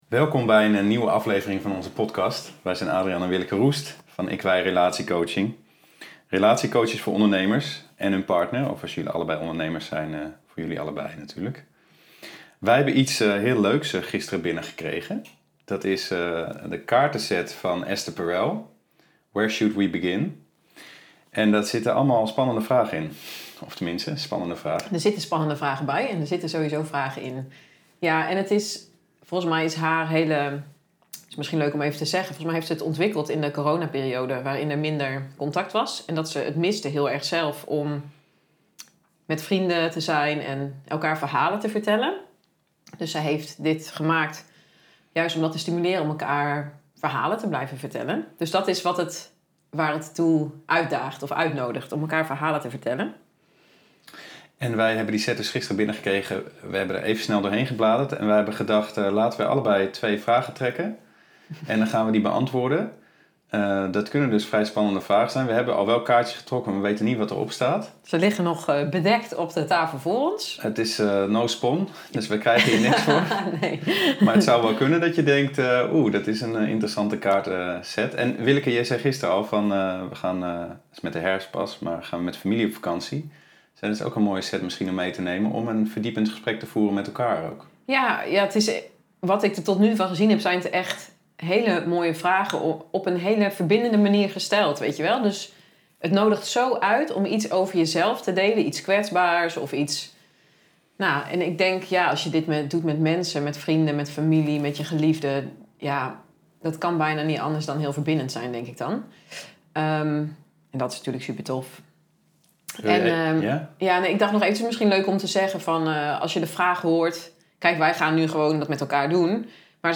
Het werden vier vragen over liefde en seks. We zijn erg blij met het gesprek.